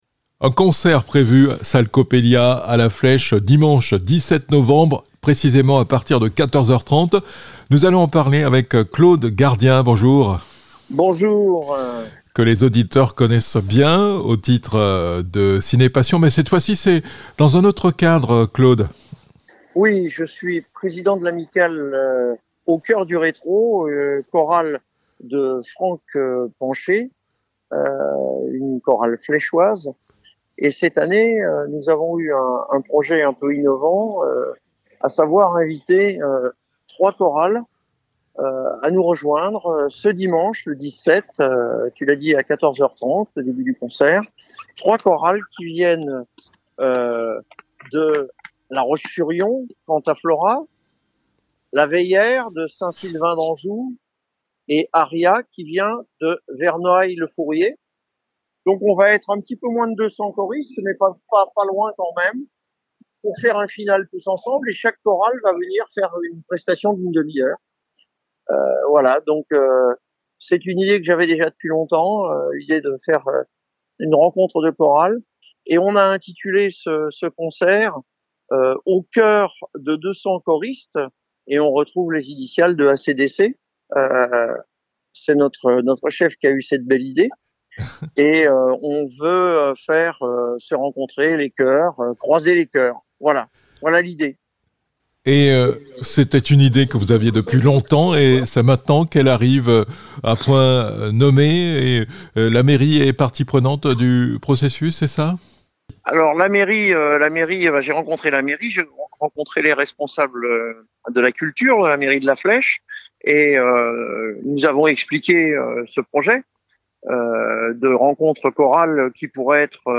Au Choeur de 200 Choristes à La Flèche